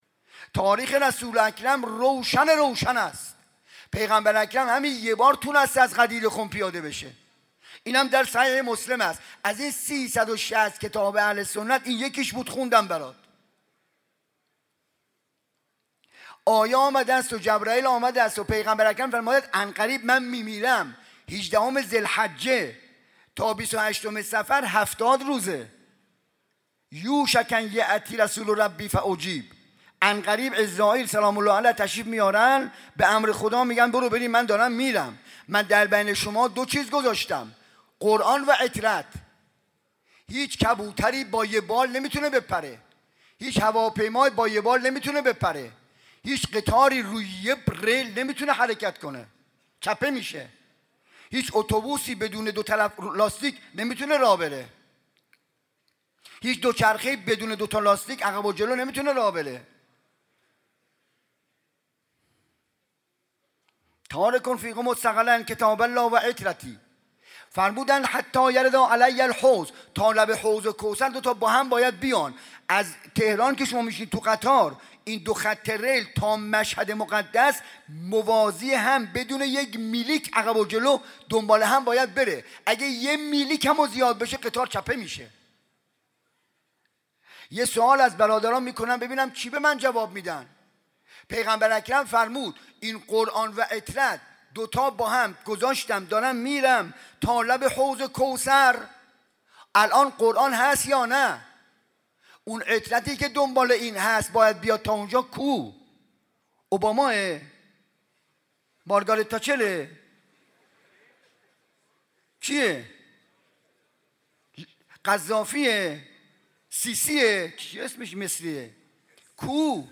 شب هفتم محرم 95_سخنراني_بخش چهارم